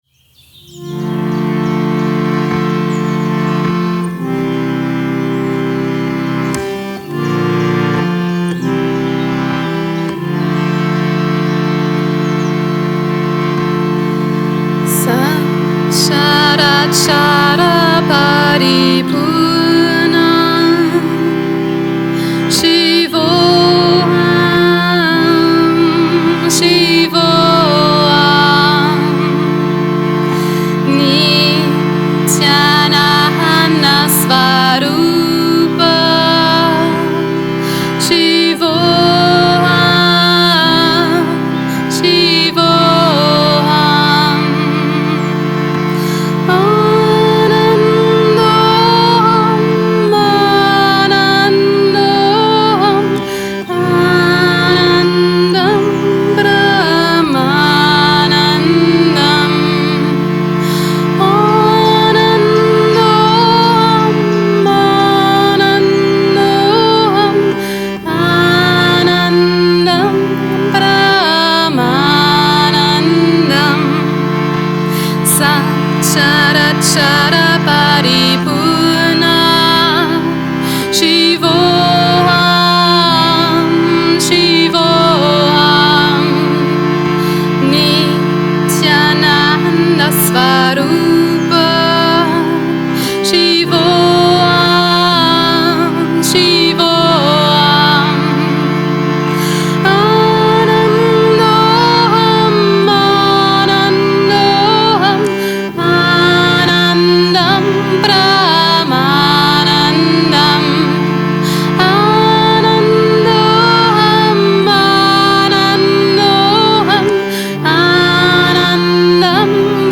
chant the mantra
on a Saturday evening satsang at Yoga Vidya in Bad Meinberg, Germany